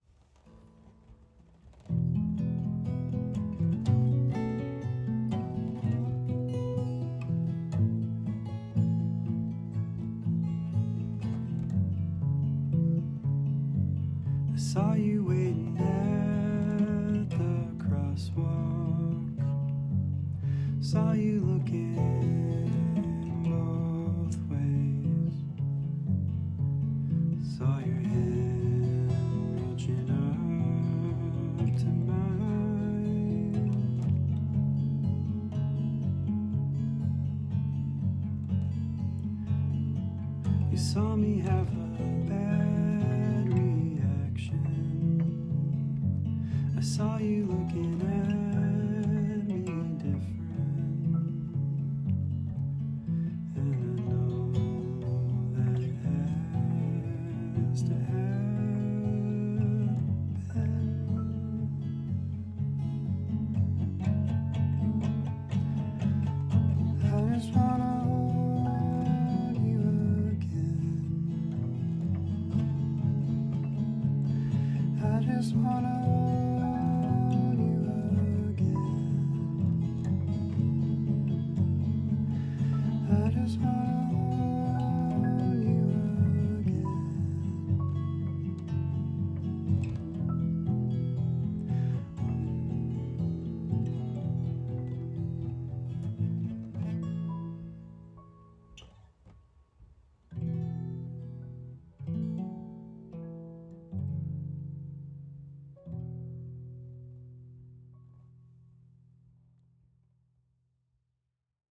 The touch of piano at the end is perfect.
the way you use the keys in an ambient way is really neat.
It makes itself heard in ways like a ghost hiding in the walls.